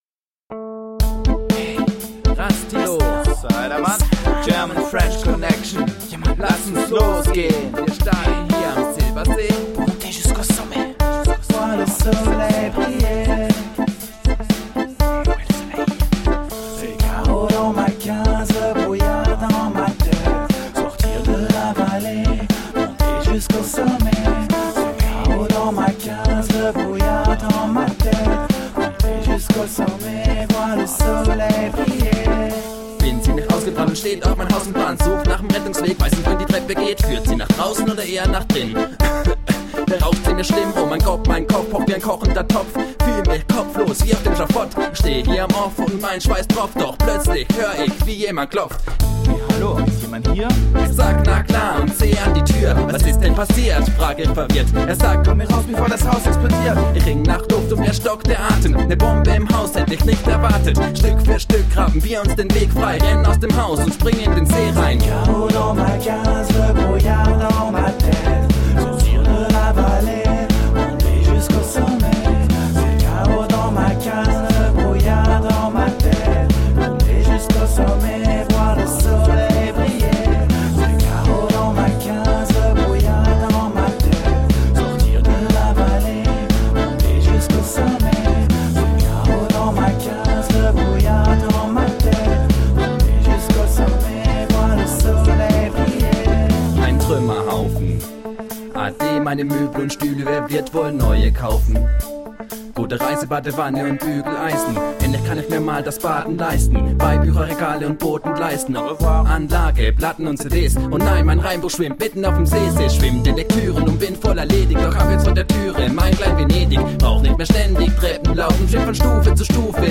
Dub, reggae, hip hop and world music from the heart.